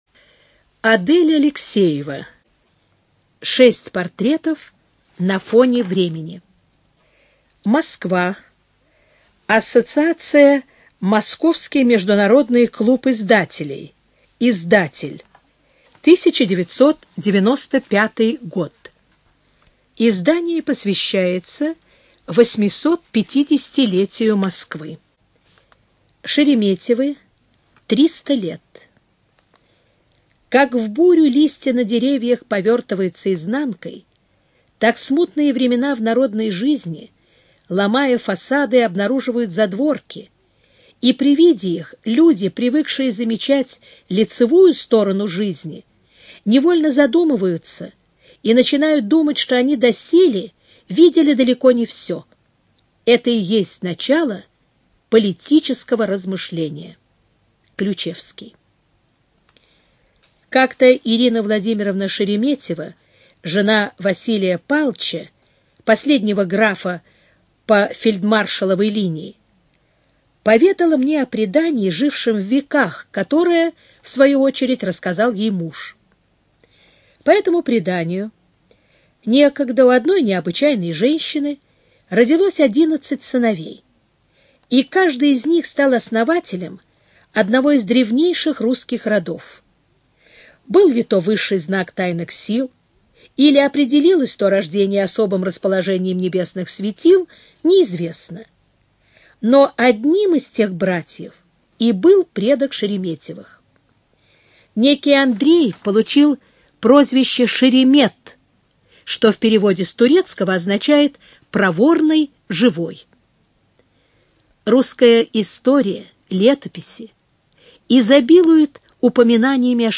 ЖанрИсторическая проза
Студия звукозаписиЛогосвос